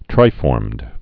(trīfôrmd) also tri·form (-fôrm)